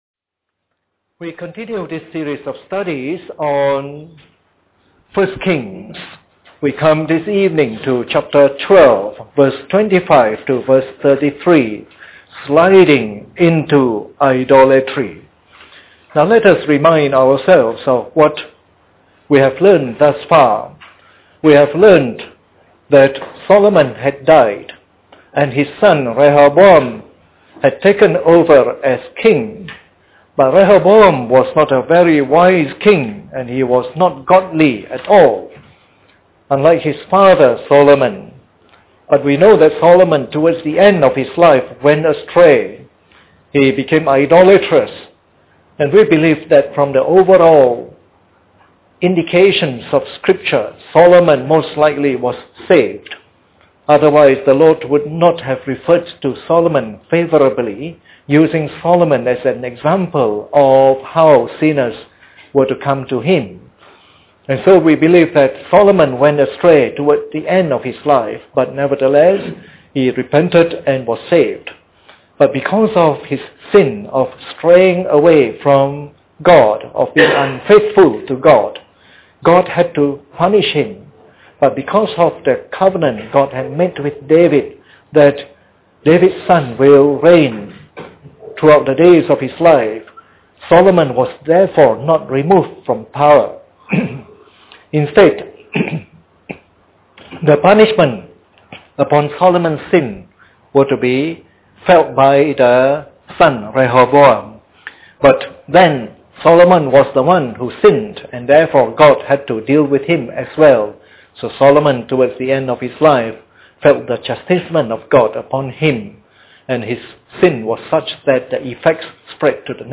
Part of the “1 Kings” message series delivered during the Bible Study sessions.